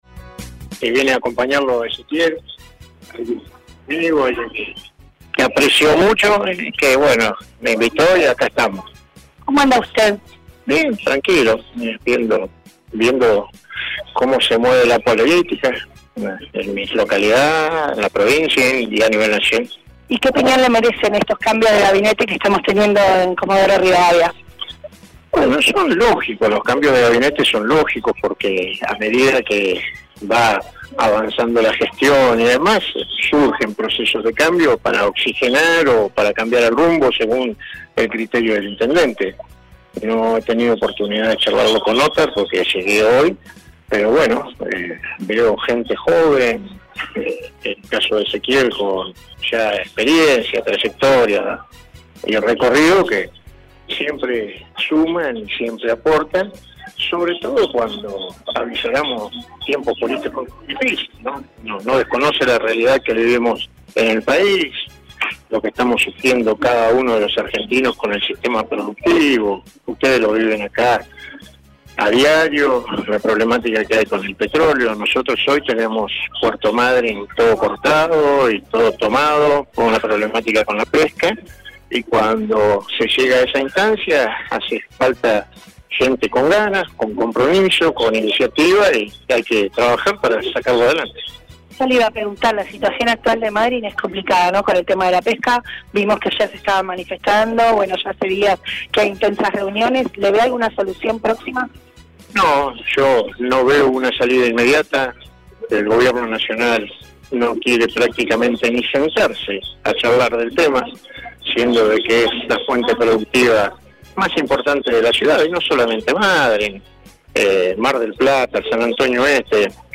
El ex intendente de Puerto Madryn, Carlos Eliceche, estuvo presente en el acto de asunción de los nuevos integrantes del gabinete de Othar Macharashvili y por supuesto, dialogó con RADIOVISIÓN.